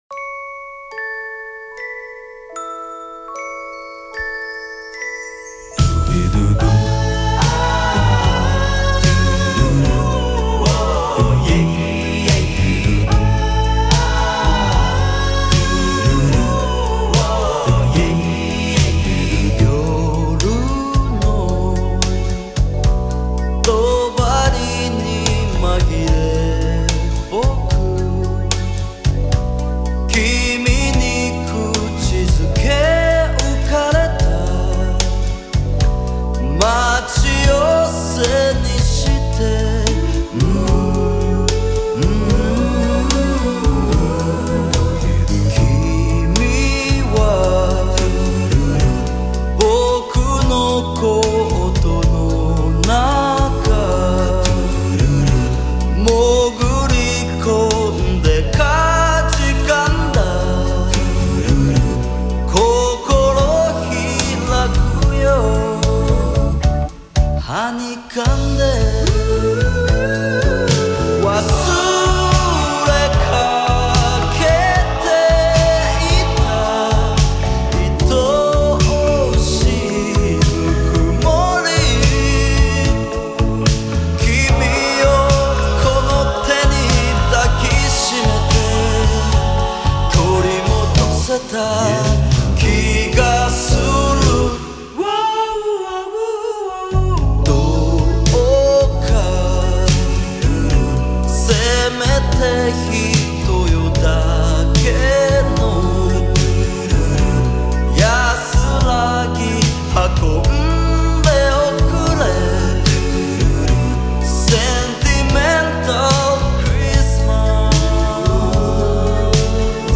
原唱版